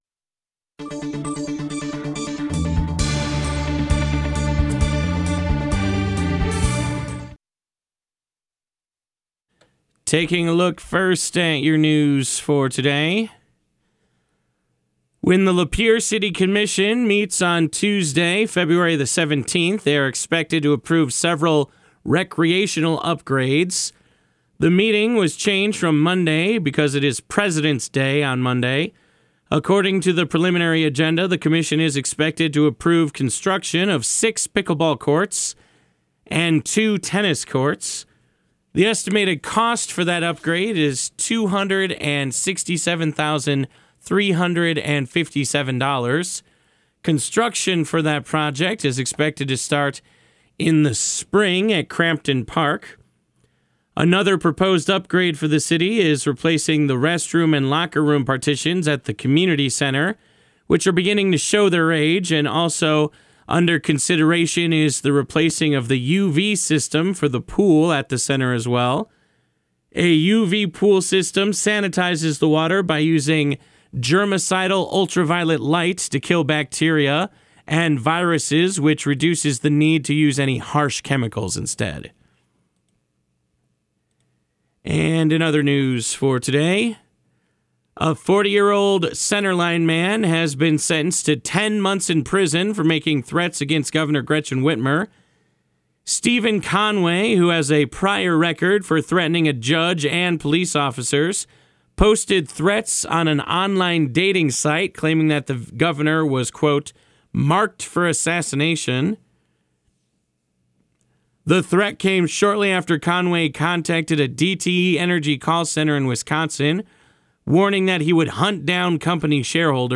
This newscast brought to you by these fine sponsors: